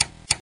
clock_tick.mp3